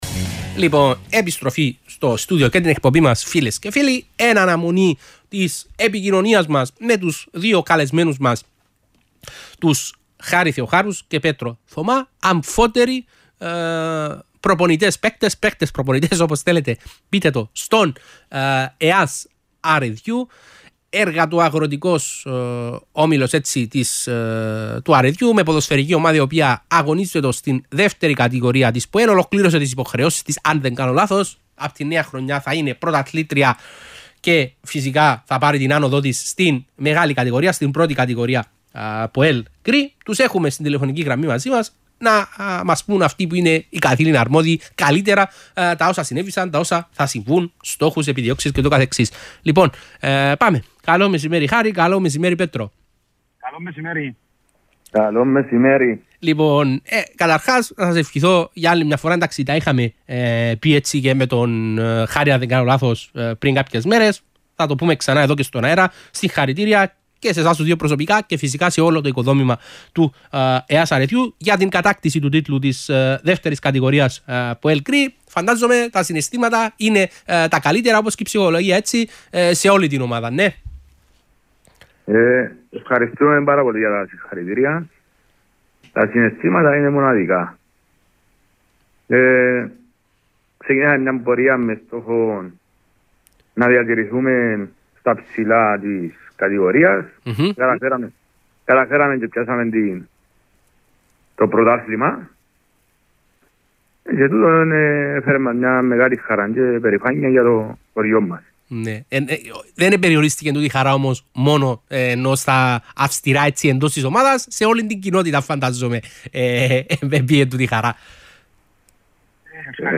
ΕΑΣ Αρεδιού: Οι ραδιοφωνικές δηλώσεις των Πρωταθλητών Β’ Κατηγορίας ΠΟΕΛ – GREE